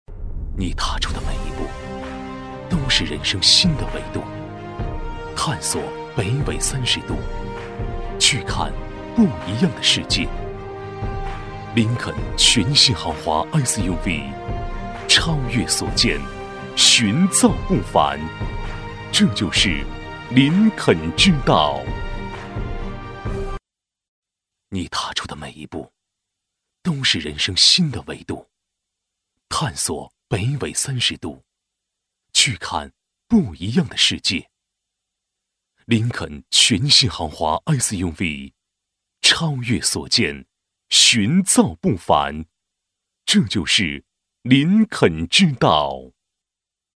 男88-汽车广告《林肯豪华SUV》-大气品质
男88-汽车广告《林肯豪华SUV》-大气品质.mp3